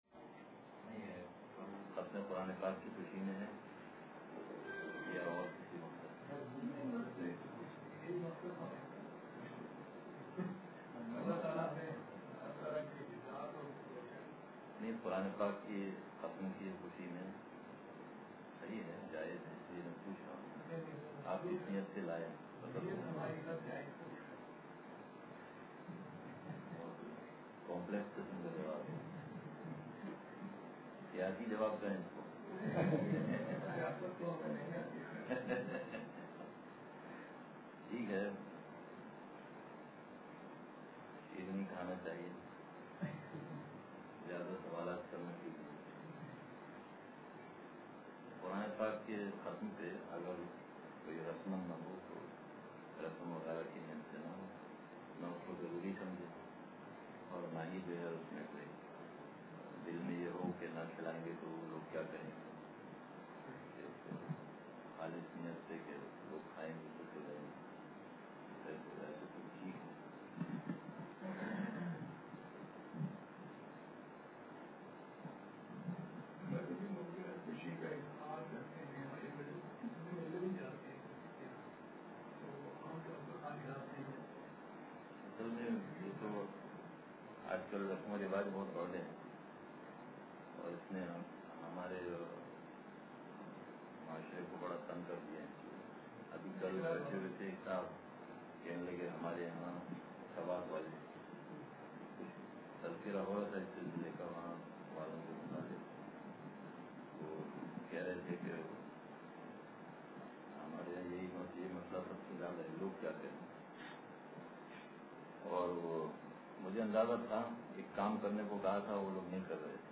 اتوار بیان